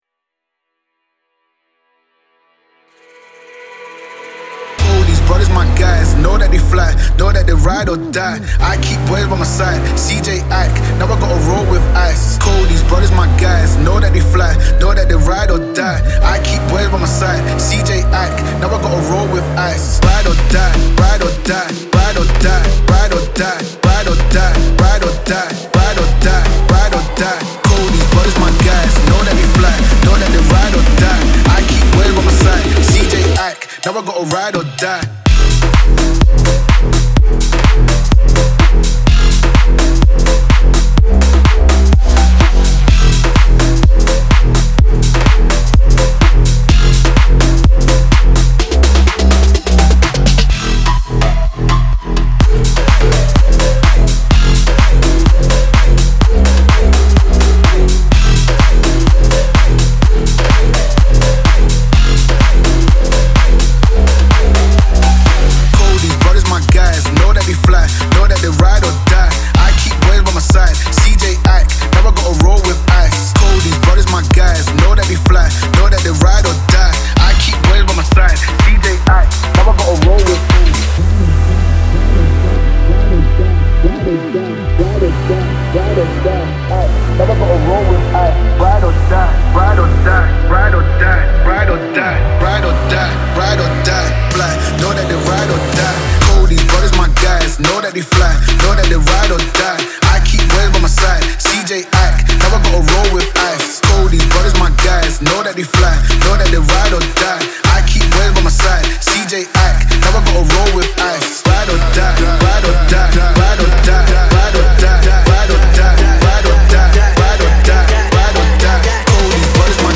Future House, Energetic, Dark, Gloomy, Angry, Restless